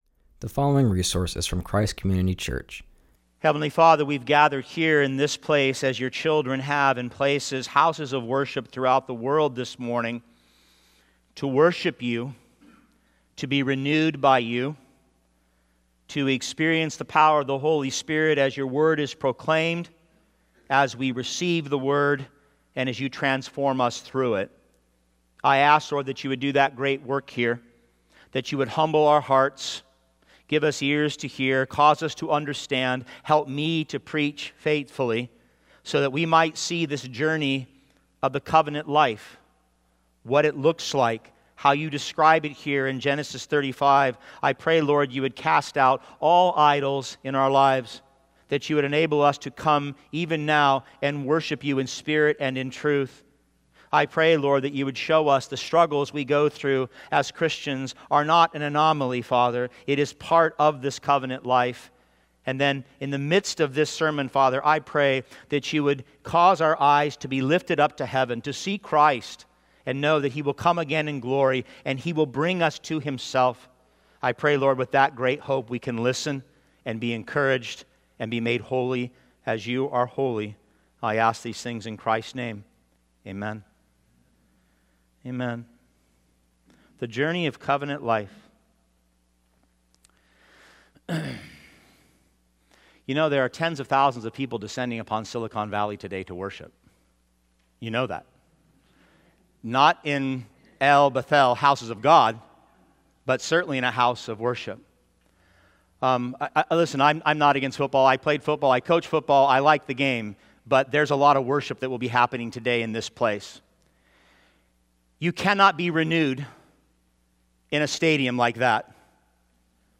preaches from Genesis 35:1-29.